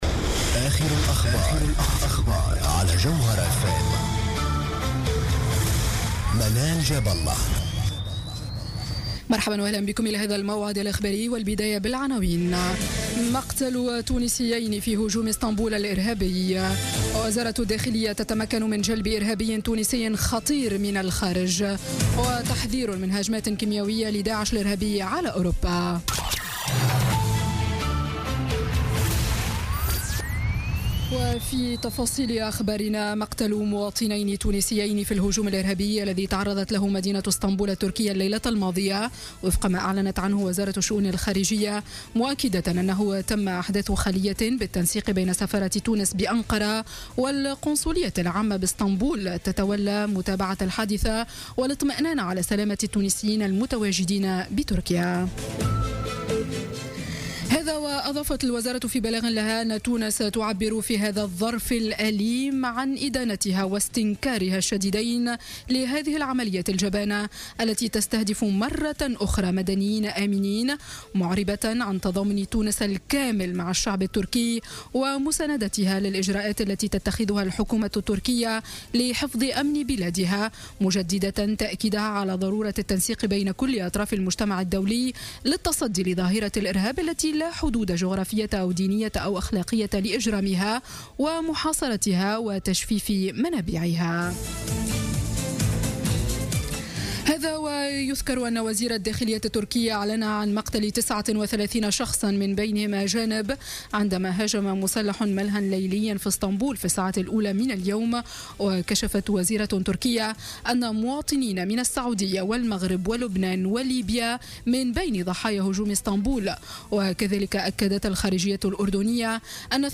نشرة أخبار السابعة مساء ليوم الأحد غرة جانفي 2017